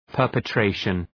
Προφορά
{,pɜ:rpı’treıʃən}